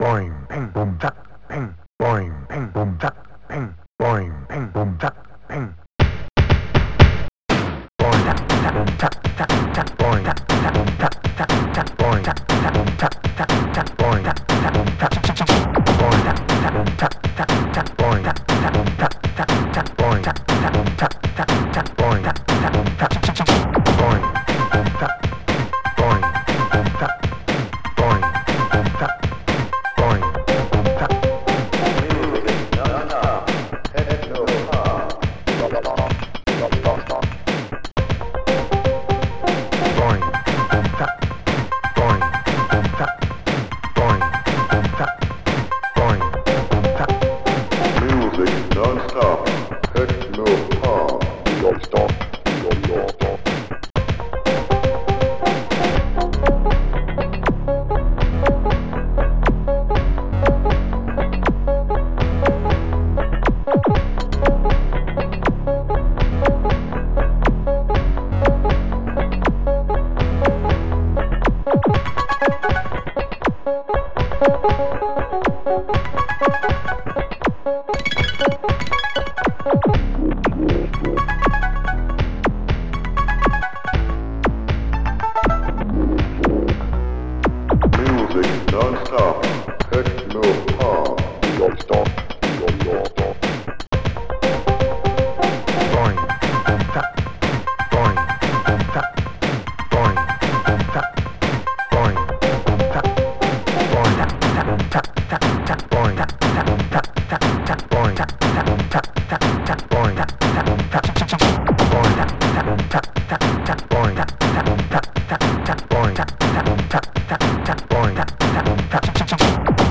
SoundTracker Module
2 channels
technopop